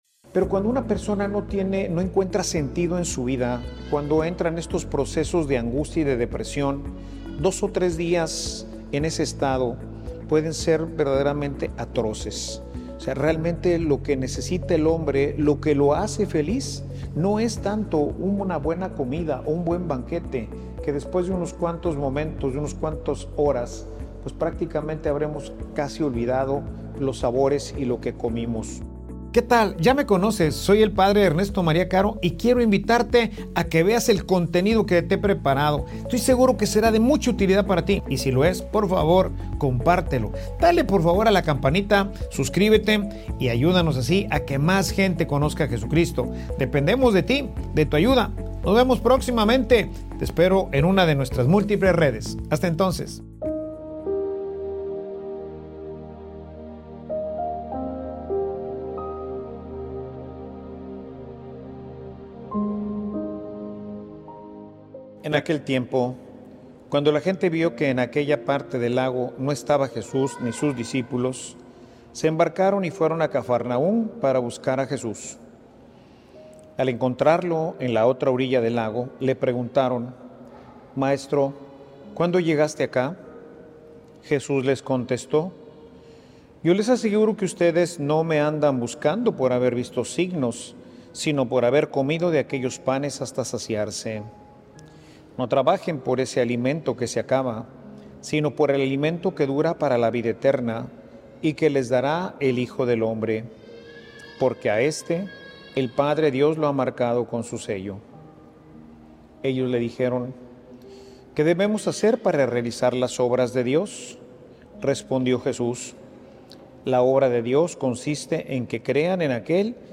Homilia_Por_que_me_buscas.mp3